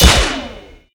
🌲 / mods PD2-AdvancedCrosshairs-r39 assets snd hitsounds